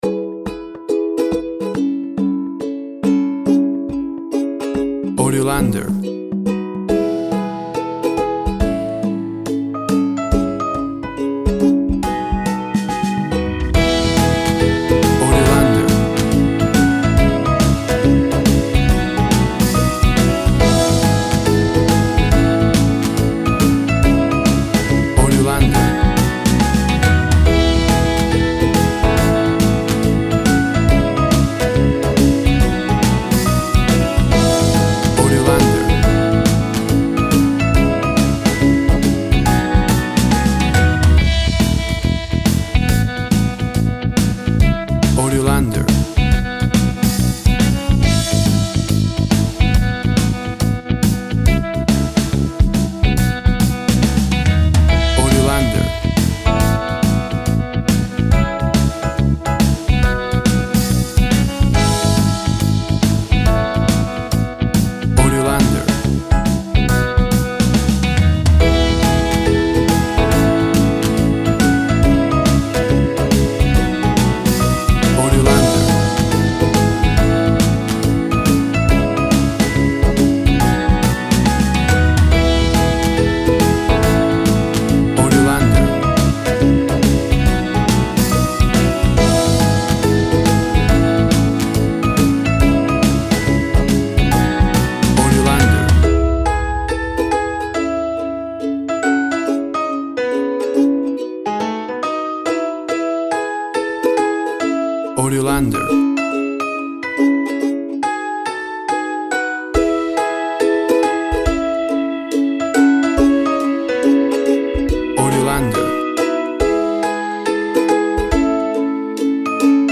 fun,upbeat, and care free with an indie pop rock style sound
WAV Sample Rate 16-Bit Stereo, 44.1 kHz
Tempo (BPM) 140